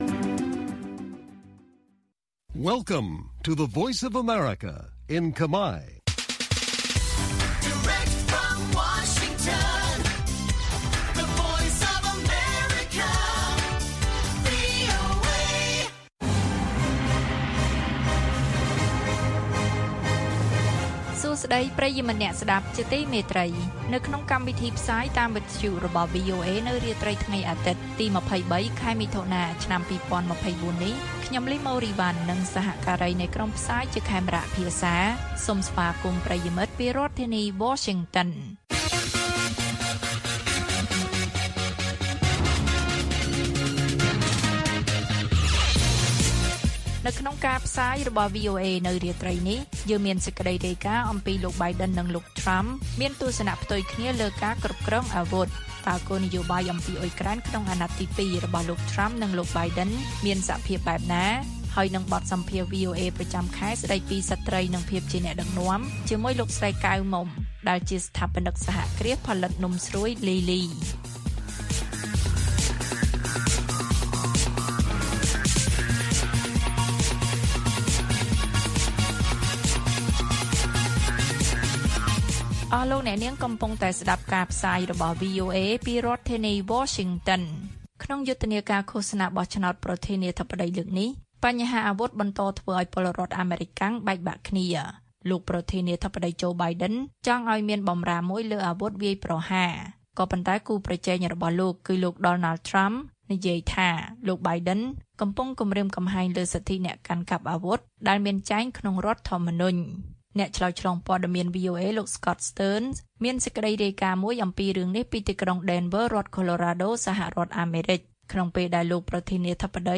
ព័ត៌មានពេលរាត្រី ២៣ មិថុនា៖ លោក Biden និងលោក Trump មានទស្សនៈផ្ទុយគ្នាលើការគ្រប់គ្រងអាវុធ